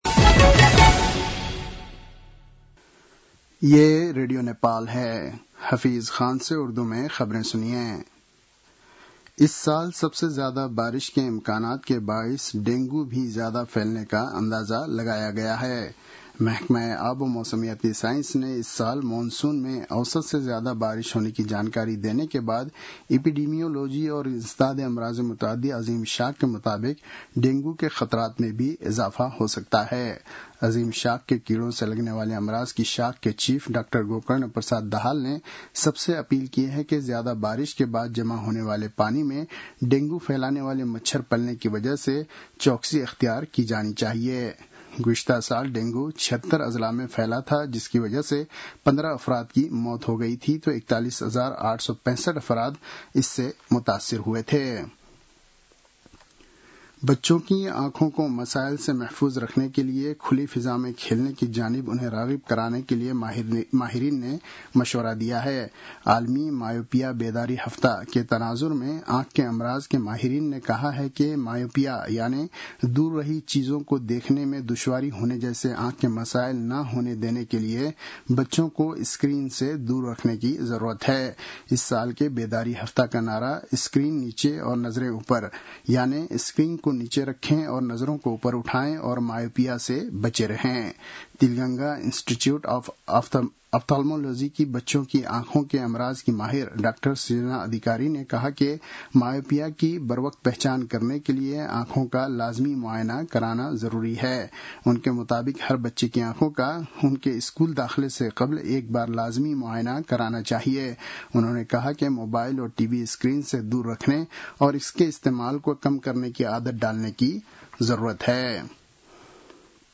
उर्दु भाषामा समाचार : ९ जेठ , २०८२
Urdu-news-2-09.mp3